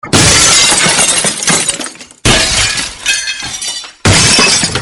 Breaking Glass Sound ringtone free download
Sound Effects